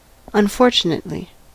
Ääntäminen
IPA: /ˈlaɪ̯dɐ/